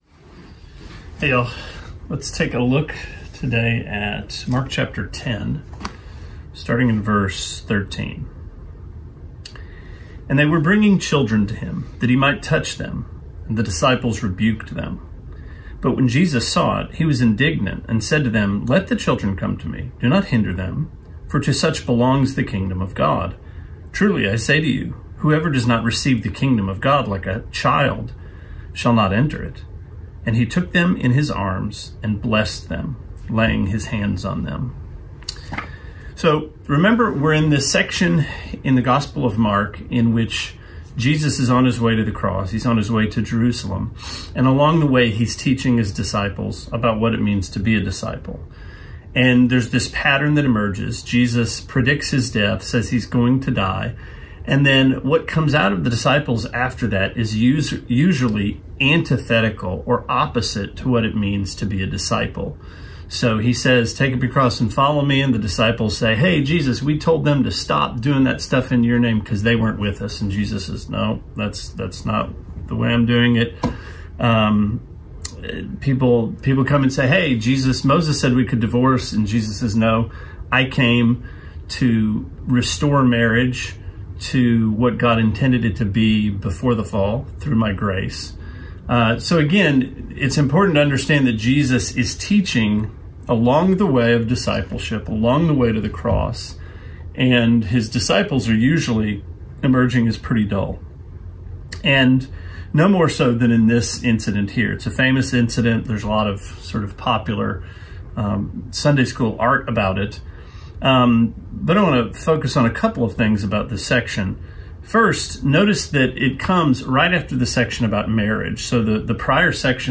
Sermonette 7/22: Mark 10:13-16: Bring On the Children